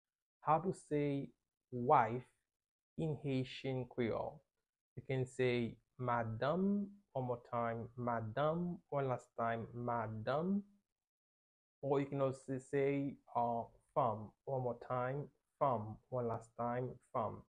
How to say "Wife" in Haitian Creole - "Madanm" pronunciation by a native Haitian Teacher
“Madanm” or “Fanm” Pronunciation in Haitian Creole by a native Haitian can be heard in the audio here or in the video below:
How-to-say-Wife-in-Haitian-Creole-Madanm-pronunciation-by-a-native-Haitian-Teacher.mp3